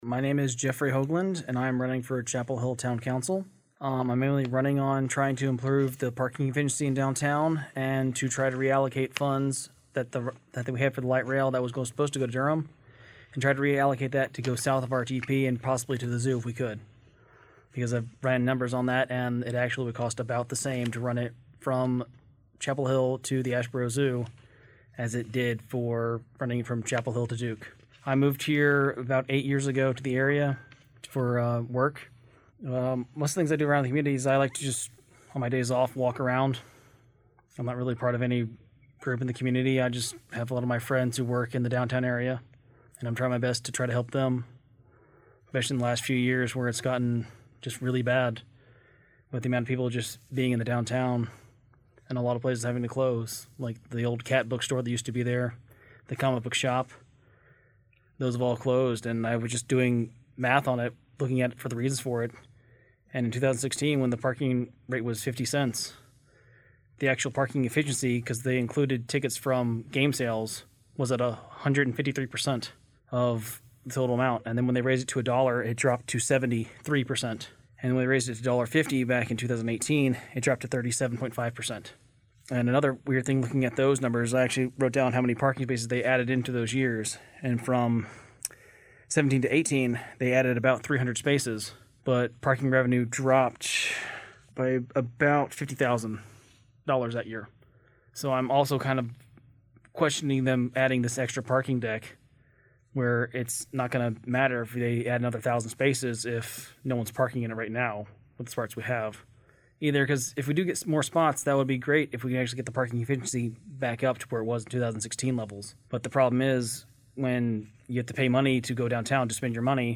During this local election season, 97.9 The Hill and Chapelboro spoke with candidates for each of the races in Chapel Hill, Carrboro and Hillsborough. Each answered the same set of questions regarding their decision to run for elected office, their background in the community and what they wish for residents to think of when voting this fall.